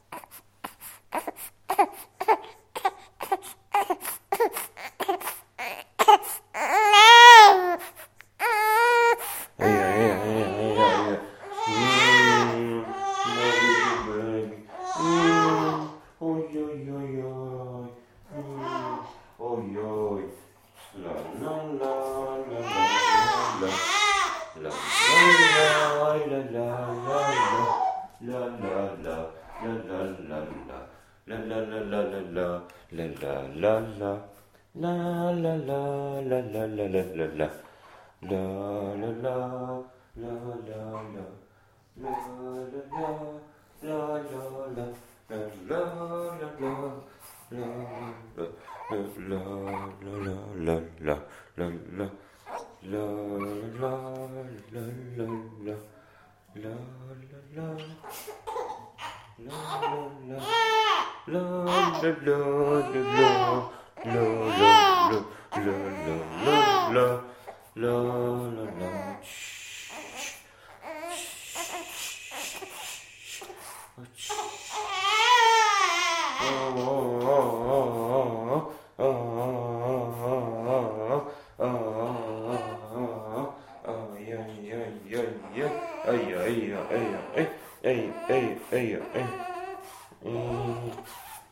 Звук папы который укладывает малыша чтобы тот уснул